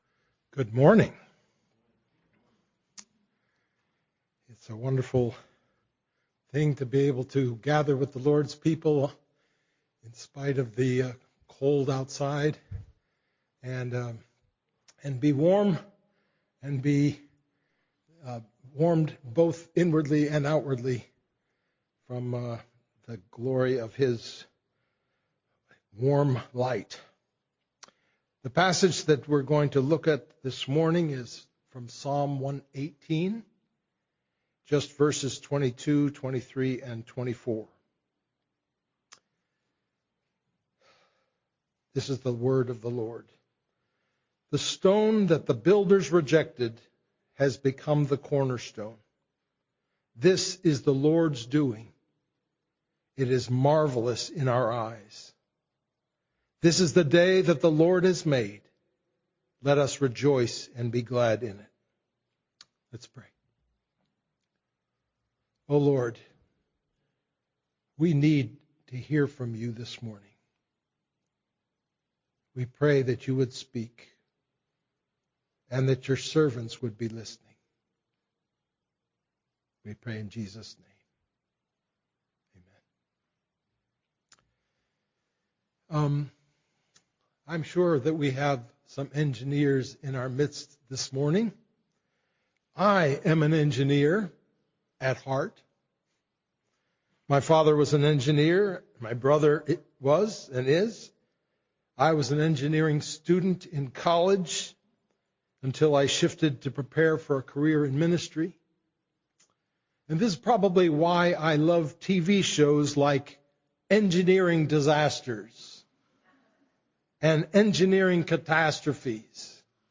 The Great Engineering Disaster: Sermon on Psalm 118 - New Hope Presbyterian Church
february-1-2026-sermon-only.mp3